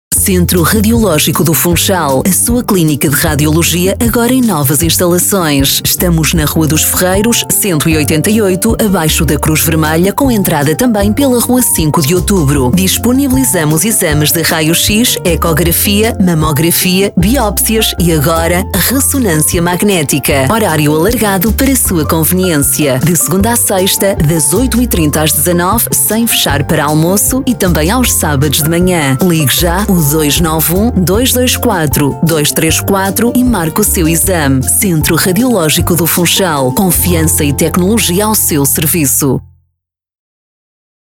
Spot publicitário da rádio
radio_spot.mp3